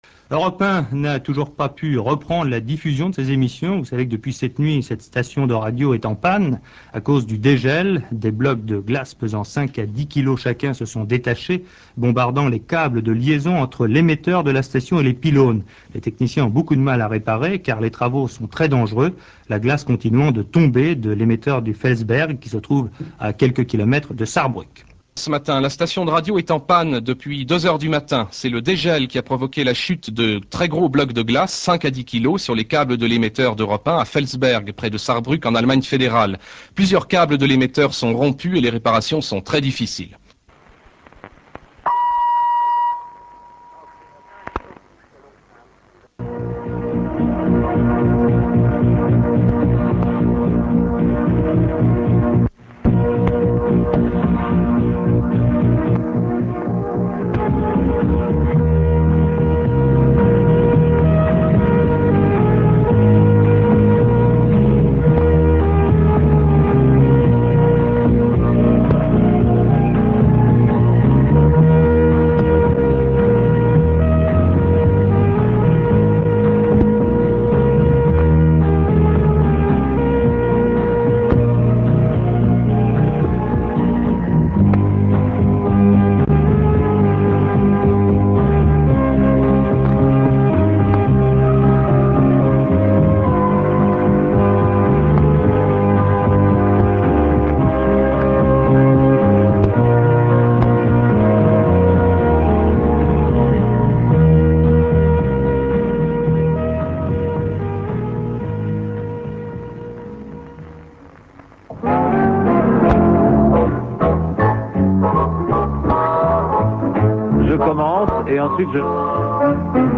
Bulletin d'info de France Inter et reprise des émissions 8'45" (Realaudio)